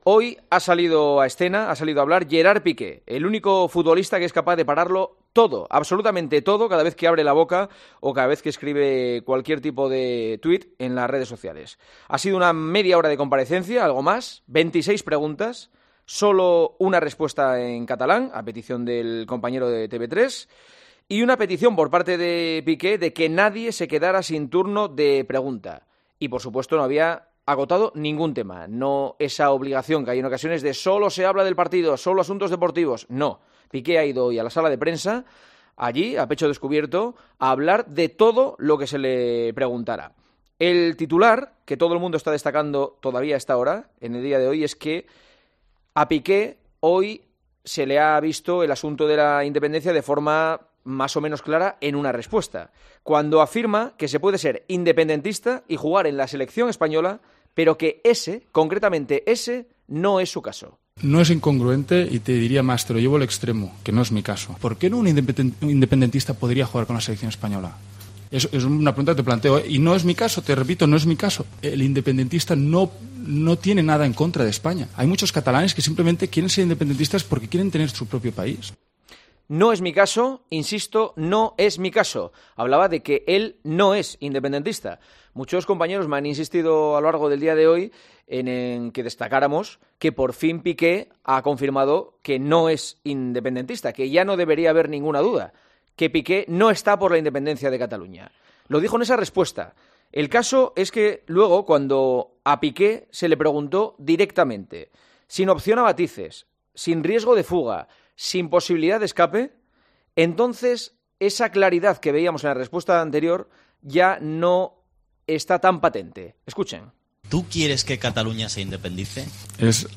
Escucha el comentario de Juanma Castaño en El Partidazo de COPE sobre la rueda de prensa que Gerard Piqué ha dado este miércoles en la concentración de la Selección española: "Piqué es valiente y se moja, pero al final, es uno más.